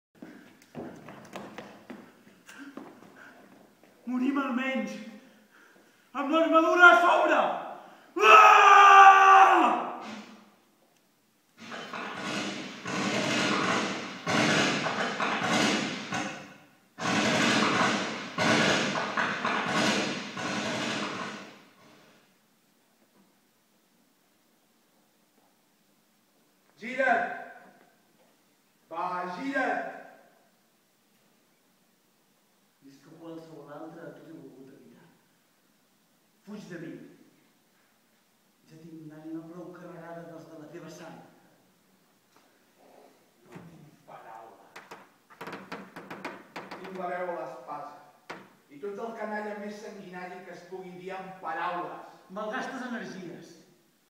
Brevíssima escenificació d'una versió de "Macbeth" estrenada per l'Aula de Teatre de la Universitat de Girona